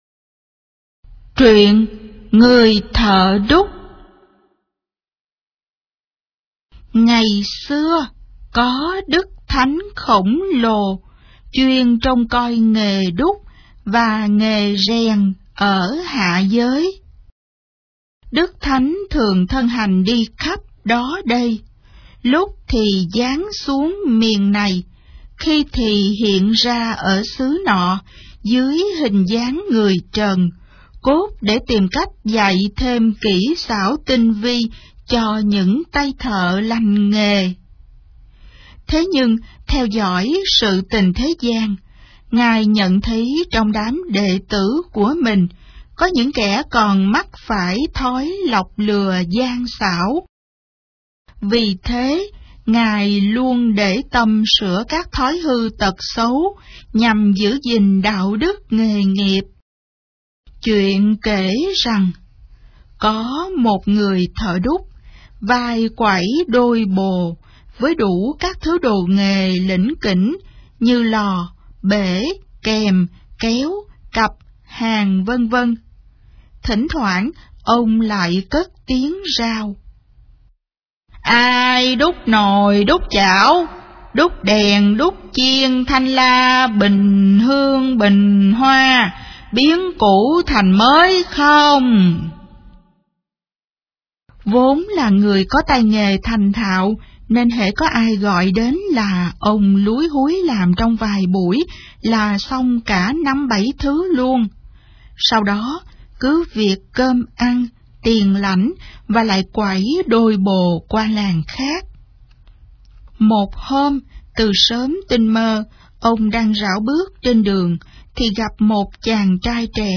Sách nói | Truyện Cổ Tích Việt Nam P17